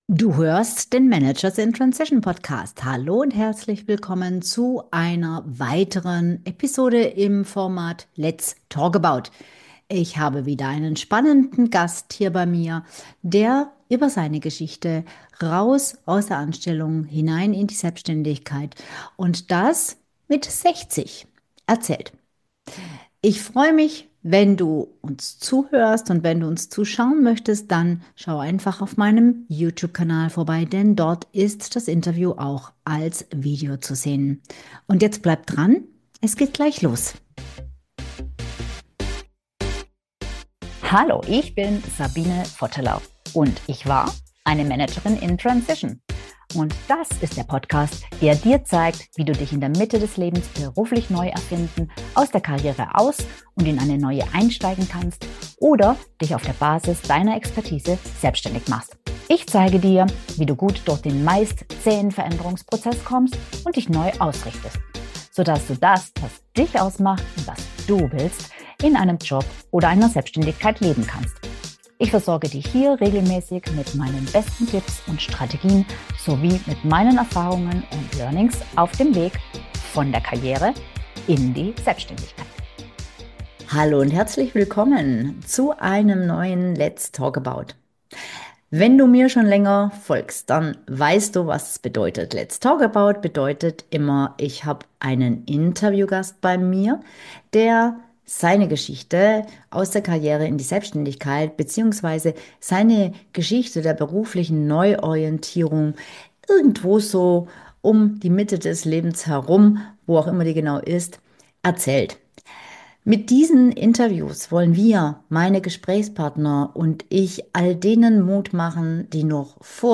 Warum er heute mit 60 neu gründet, wieso die Zielgruppe 50plus völlig unterschätzt wird, und wie wir als Gesellschaft dringend andere Bilder vom Älterwerden brauchen. Ein mega Gespräch über Selbstwert, späte Neuanfänge und die Kraft, die entsteht, wenn man sich den richtigen Fragen stellt.